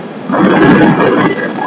Â I used the induction loop receiver to listen in to the sound of my computer.